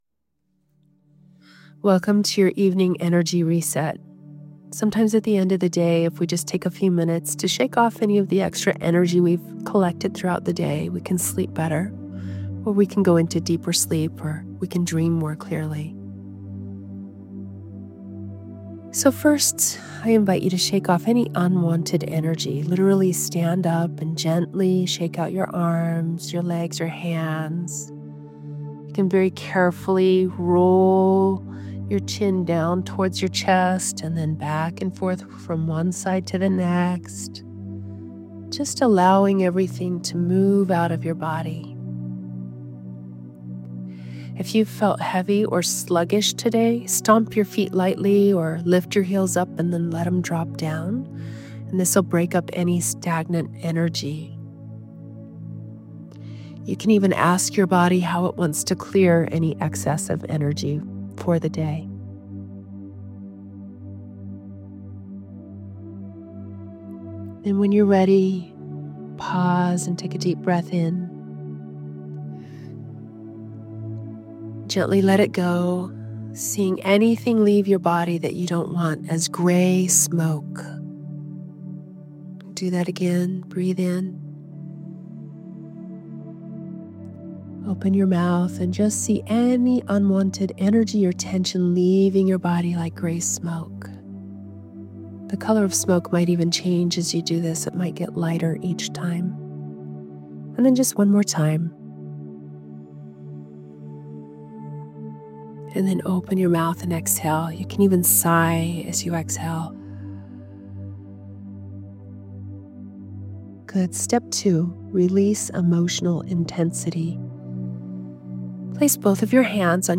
Evening Energy Reset audio. It’s a short guided practice designed to help you pause at the end of the day, release what isn’t yours to carry, and call your energy back to yourself.